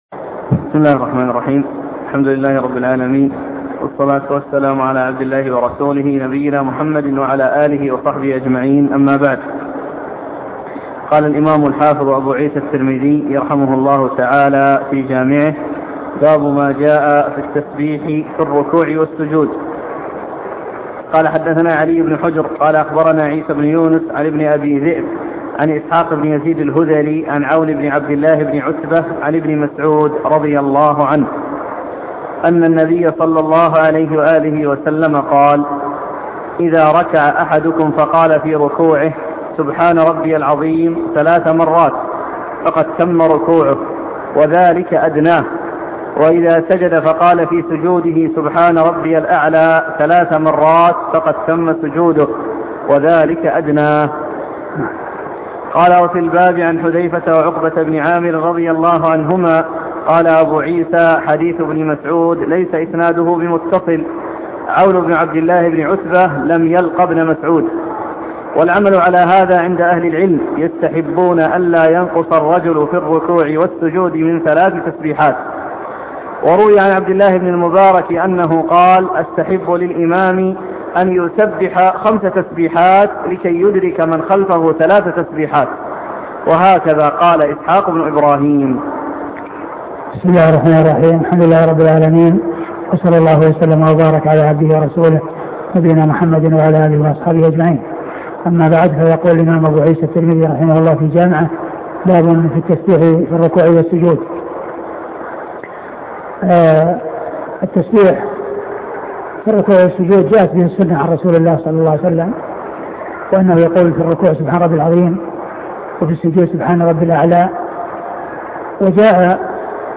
سنن الترمذي شرح الشيخ عبد المحسن بن حمد العباد الدرس 45